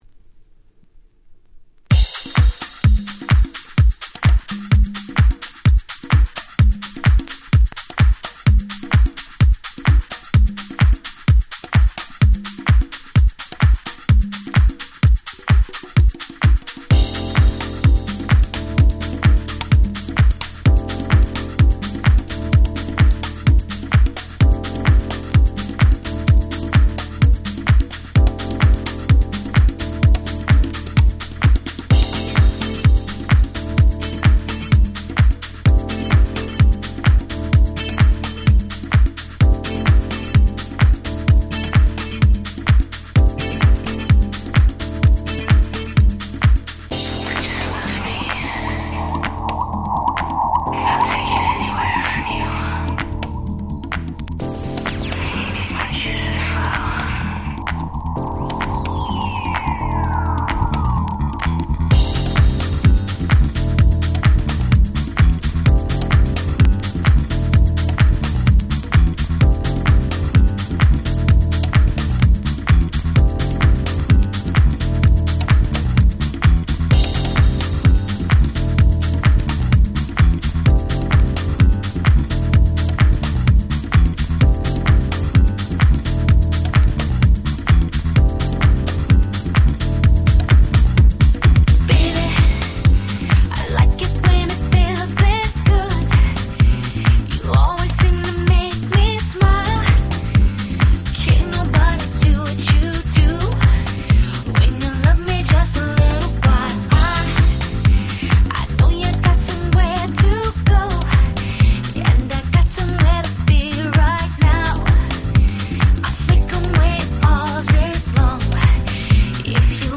B-1のHouse Remixが最高すぎるので良し！！